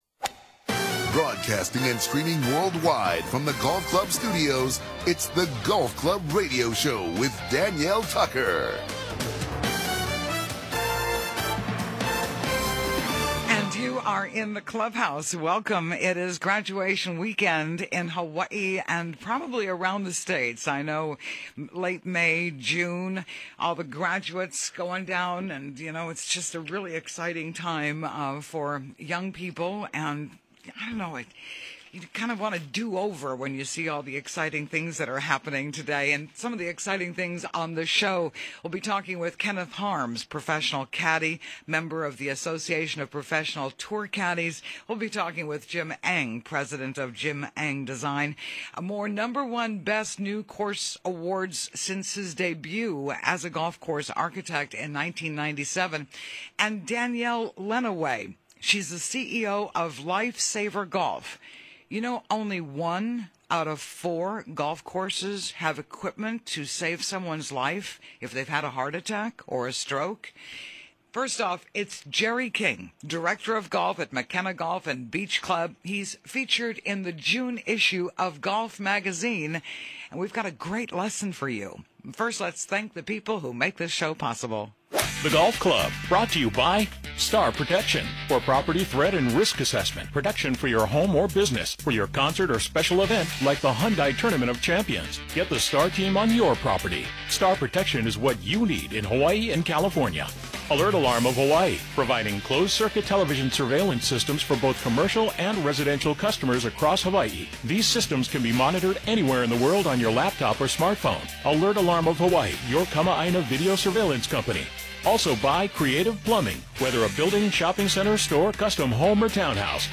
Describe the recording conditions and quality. SATURDAY MORNINGS: 7:00 AM - 8:30 AM HST MAUI OAHU KAUAI HILO KONI FM 104.7 KGU FM 99.5 KTOH FM 99.9 KPUA AM 670